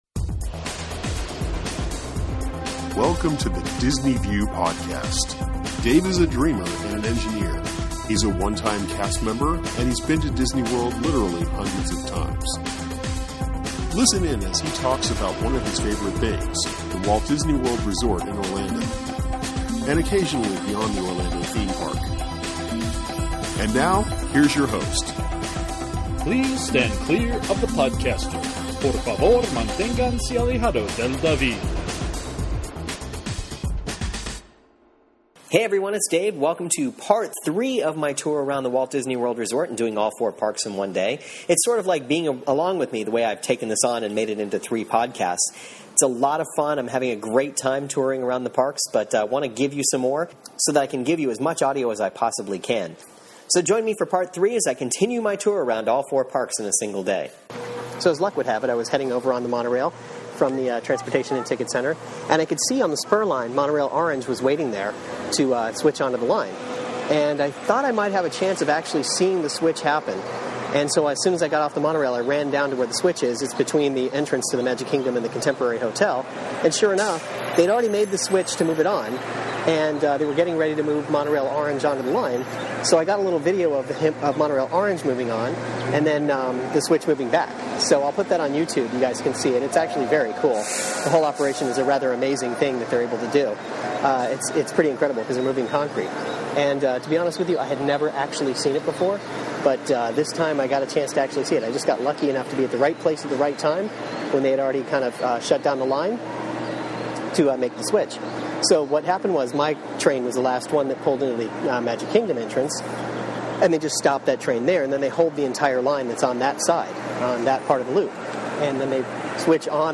On this podcast, I head over to the Magic Kingdom and end my day by riding the monorail back to my car. Of course you will hear some of my stories as I tool around.